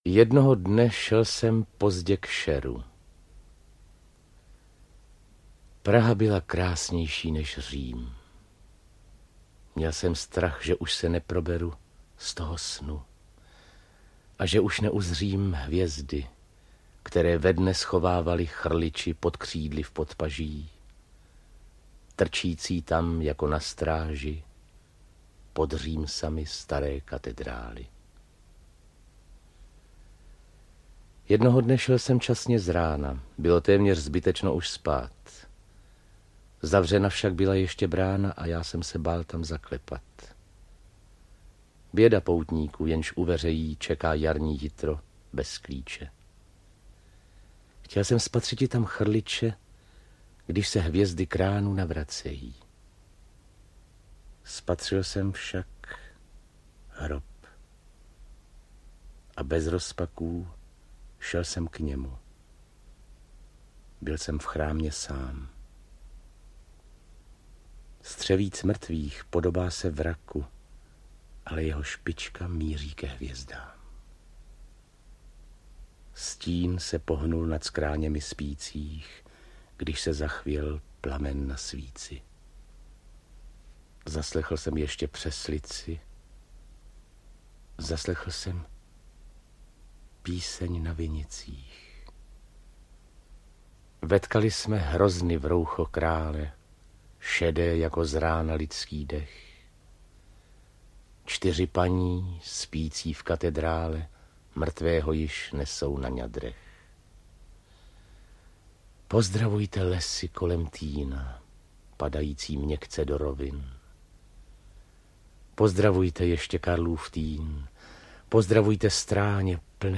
Světlem oděná audiokniha